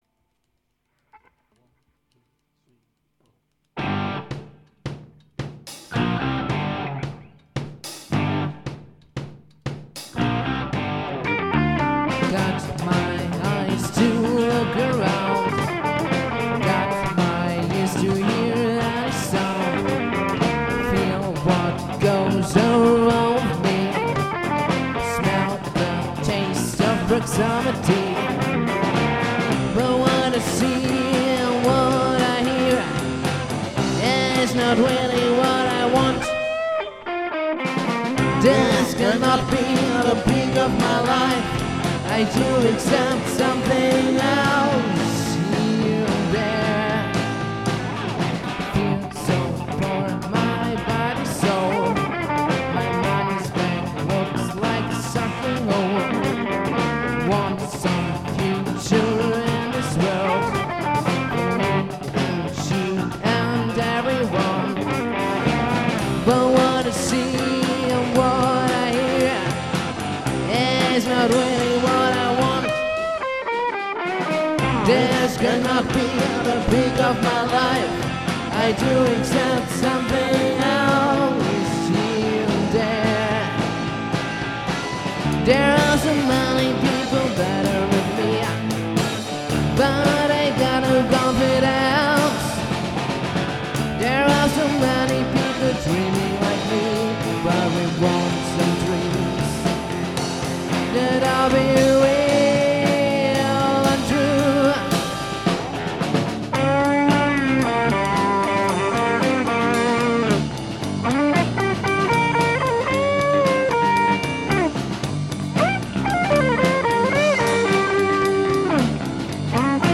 Rock-Formation
Bass
Vocals
Guitar, Vocals
Drums